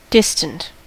distant: Wikimedia Commons US English Pronunciations
En-us-distant.WAV